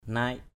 /naɪʔ/ 1.